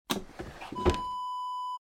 Dresser drawer close sound effect .wav #5
Description: The sound of a wooden dresser drawer being closed
Properties: 48.000 kHz 16-bit Stereo
A beep sound is embedded in the audio preview file but it is not present in the high resolution downloadable wav file.
Keywords: wooden, dresser, drawer, push, pushing, close, closing
drawer-dresser-close-preview-5.mp3